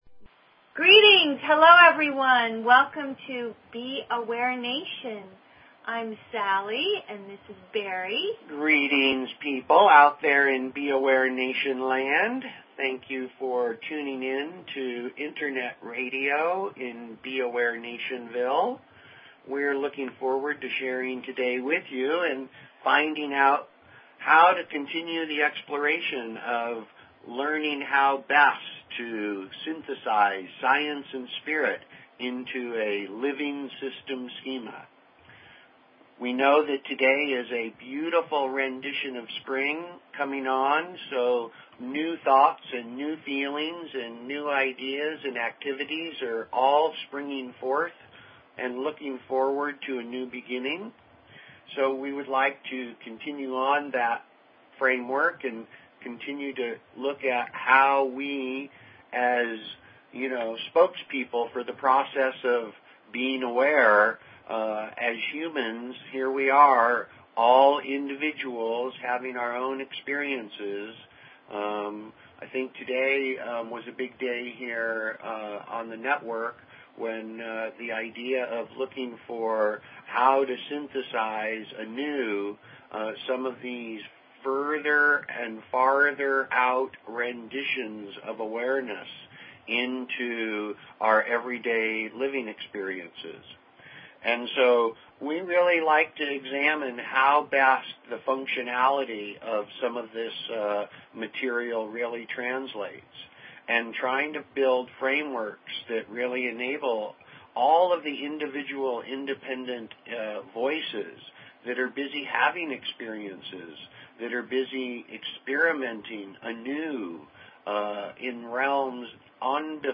Talk Show Episode, Audio Podcast, B_Aware_Nation and Courtesy of BBS Radio on , show guests , about , categorized as